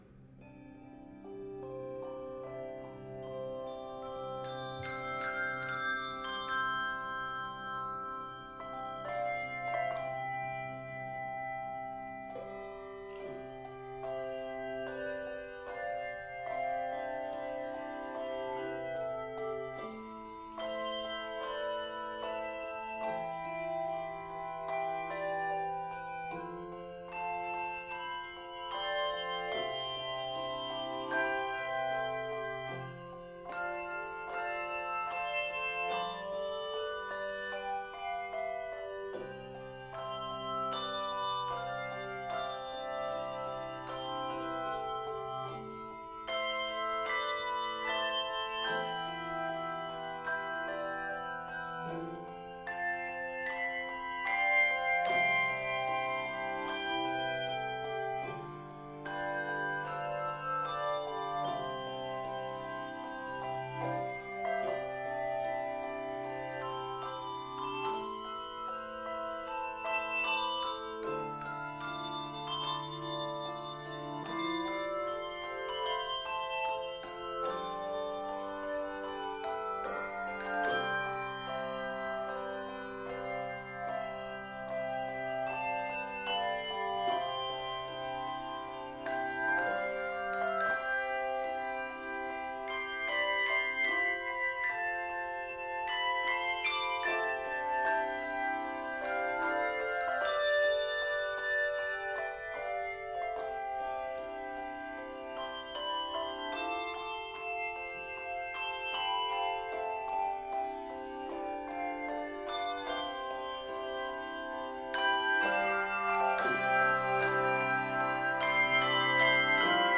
Key of C Major.
N/A Octaves: 3-5 Level